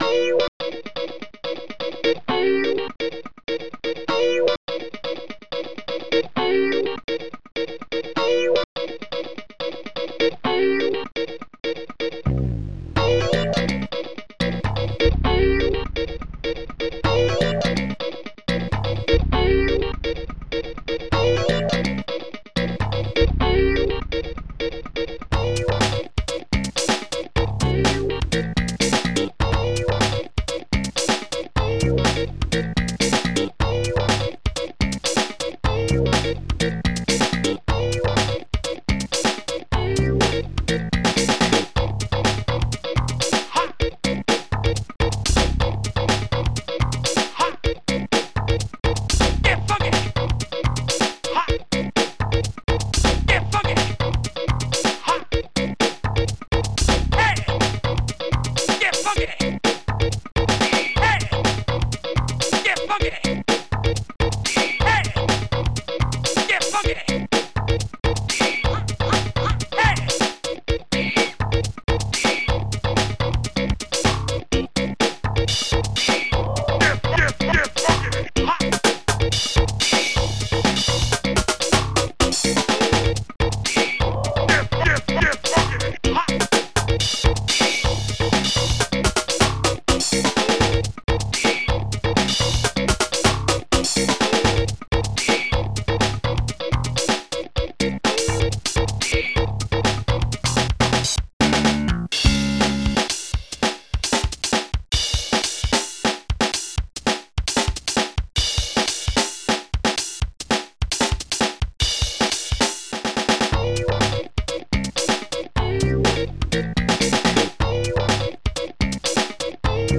funk
Protracker Module